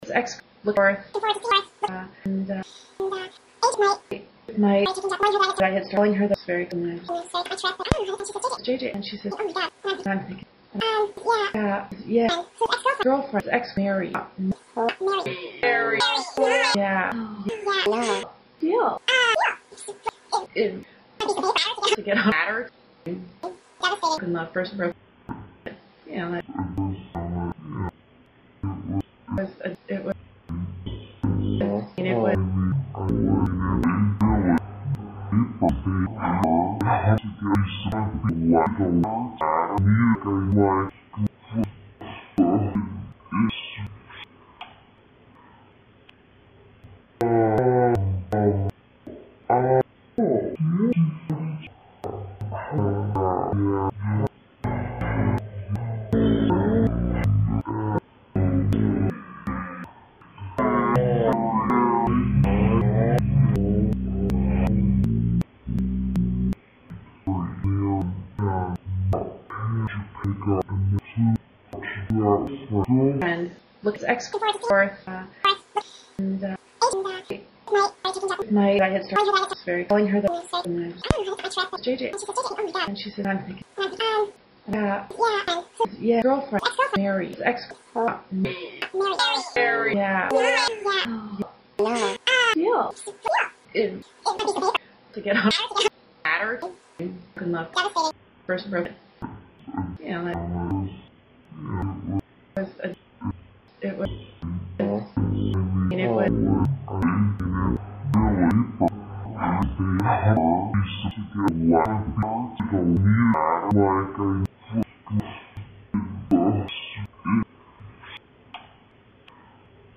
实战口语情景对话 第18期:爱情故事 The Love Story